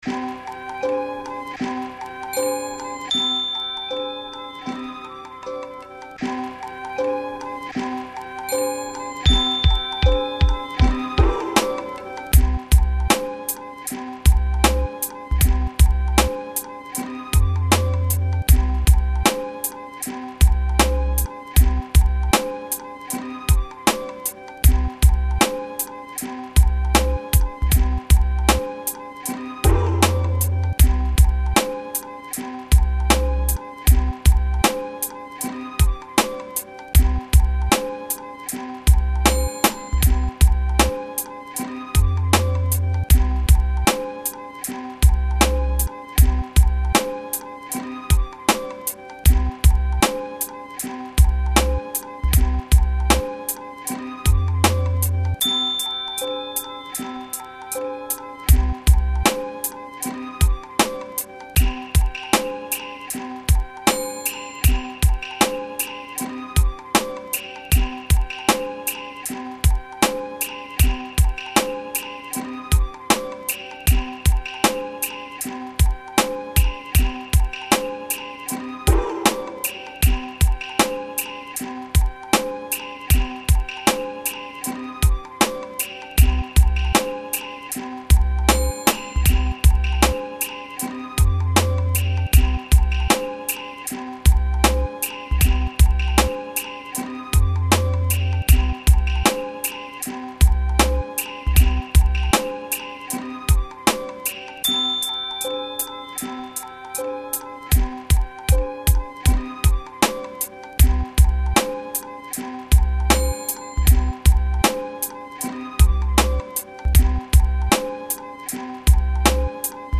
nouveau morceau aux sonorités japonaise et zen à découvrir ainsi que d'autres sur ma page productions musicales